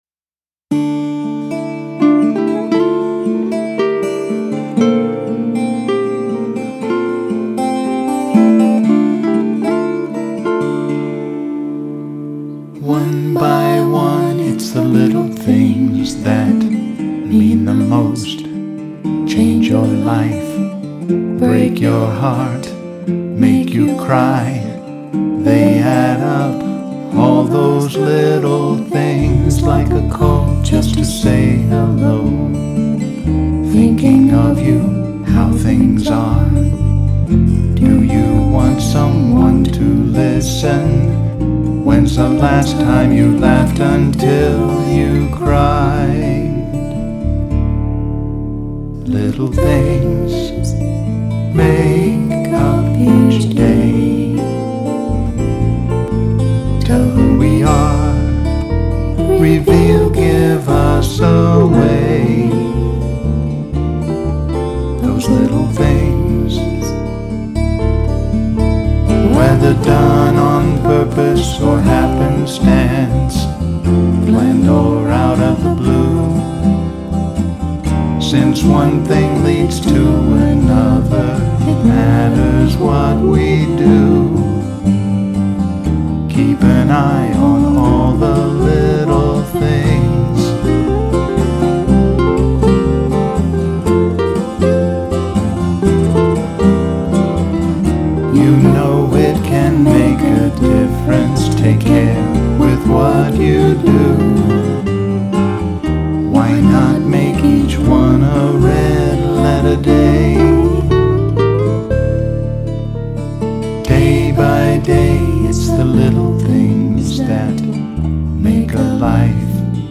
vocals and production
guitar